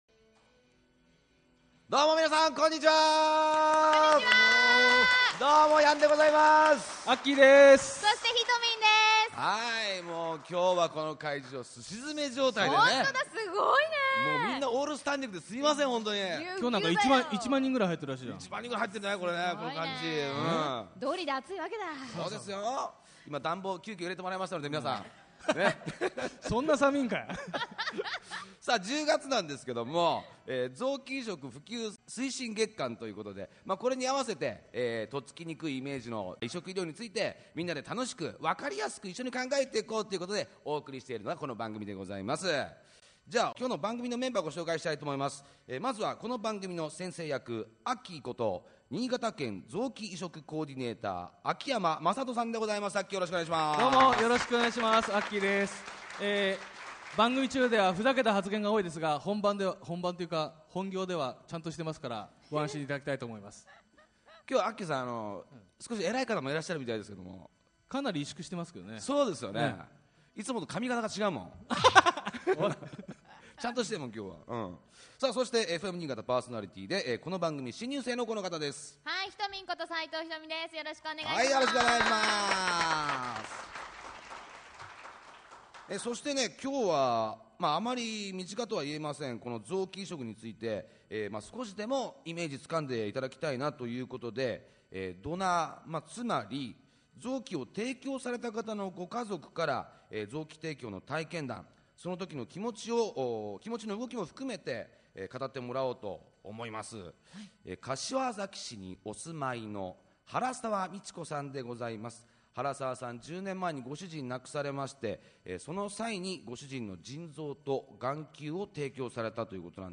第4回「臓器提供者家族の体験談（公開録音）」2013年11月03日放送
2013年10月27日に南魚沼市の北里大学 保健衛生学院で行われた「臓器移植フォーラム2013in南魚沼」での番組公開録音。
※BGMやリクエスト曲、CMはカットしています。